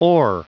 Prononciation du mot ore en anglais (fichier audio)
Prononciation du mot : ore